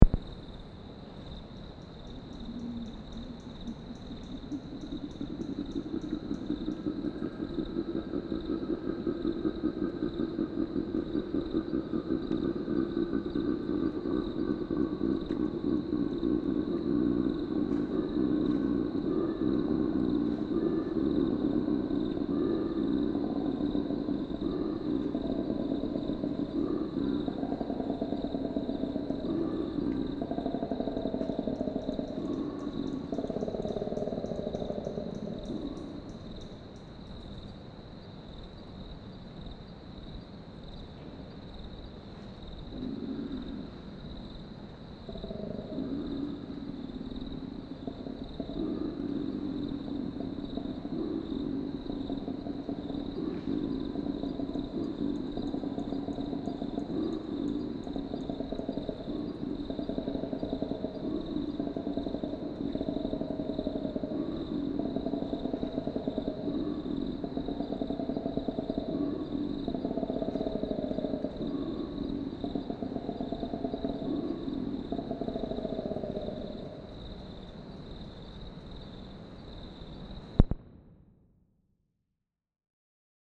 Koalas can be surprisingly loud during breeding season. Males make long, deep, guttural bellows that can be heard from hundreds of metres away to attract females and ward off other males.
koala-bellow-call.mp3